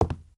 sounds / material / human / step / wood02gr.ogg
wood02gr.ogg